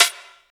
snare6.ogg